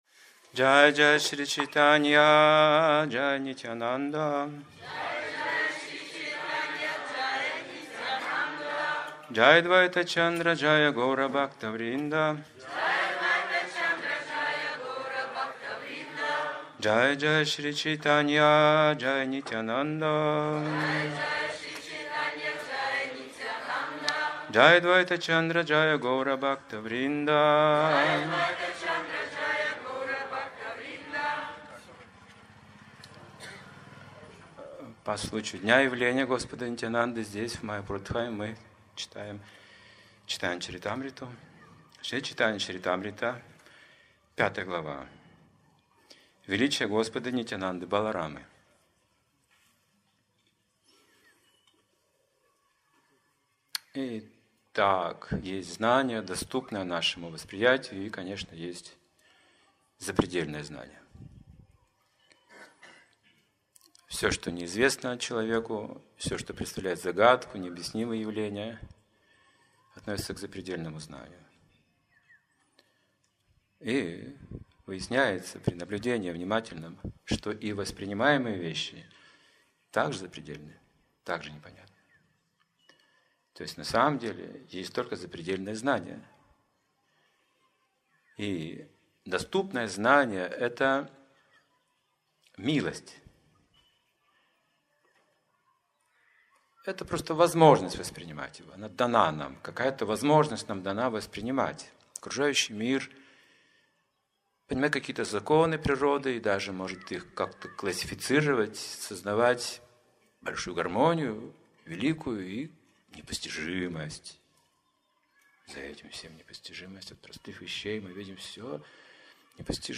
Темы, затронутые в лекции